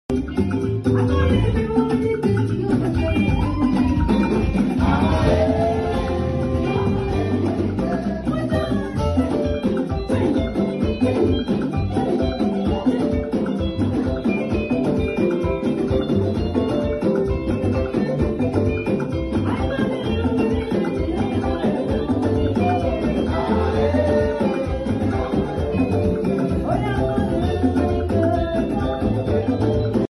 Praise session with minister Judikay. sound effects free download